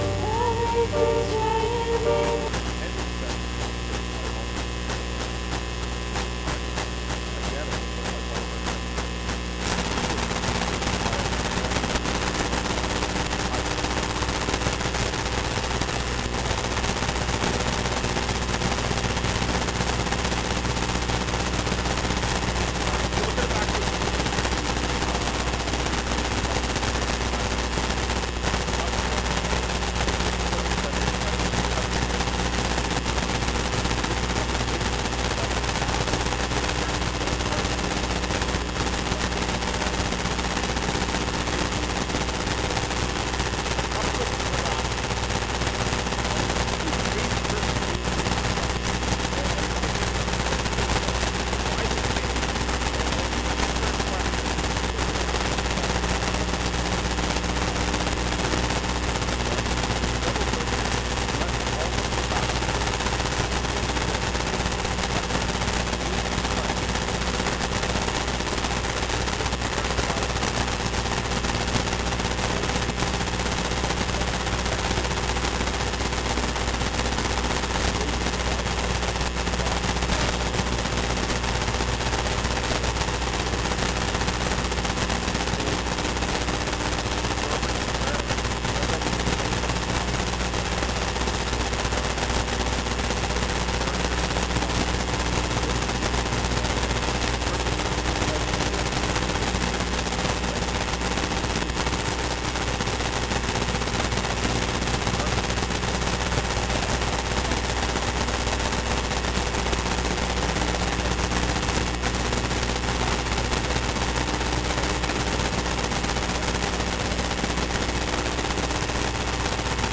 Oct 20, 2024 Bold Preaching (2 Timothy 4:1-8) MP3 SUBSCRIBE on iTunes(Podcast) Notes Discussion Sermons in this Series This sermon was recorded in Salmon Arm and preached in both SA and Enderby.